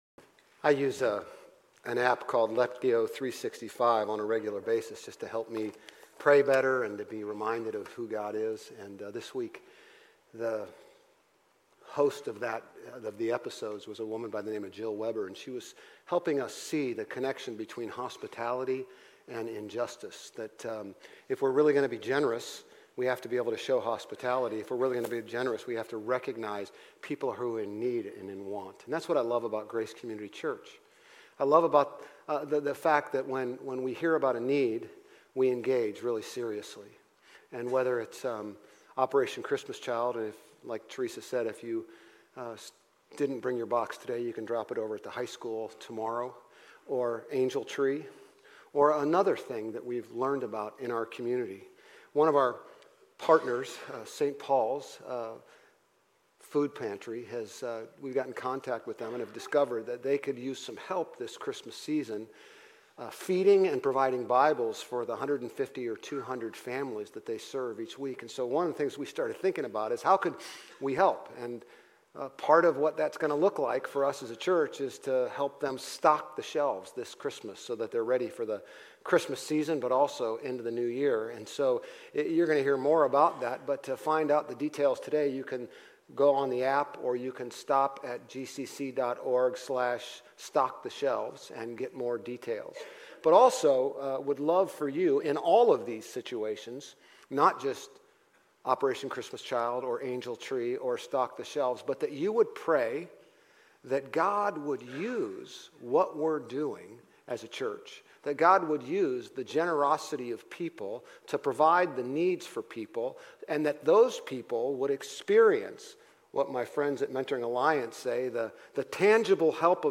Grace Community Church Old Jacksonville Campus Sermons Gen 37-50 - Joseph Nov 24 2024 | 00:33:21 1x 00:00 / 00:33:21 Subscribe Share RSS Feed Share Link Embed